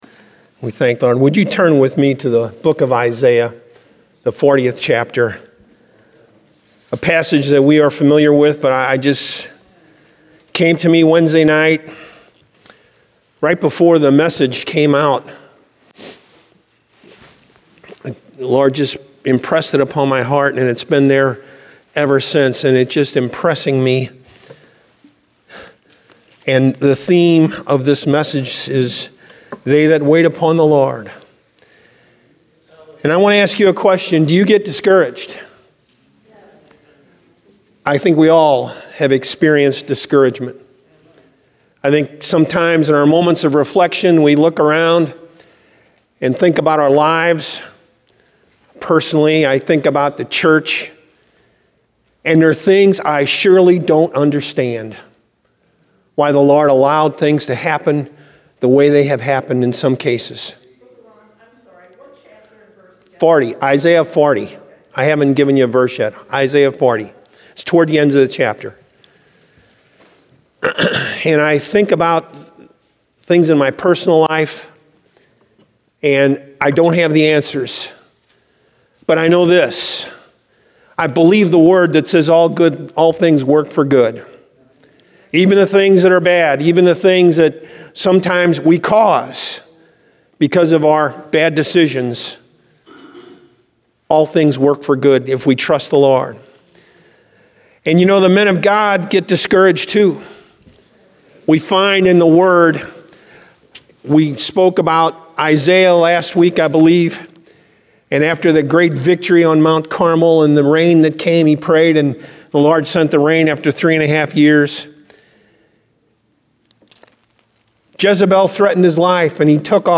Download Sermon Audio File Evangelical Full Gospel Assembly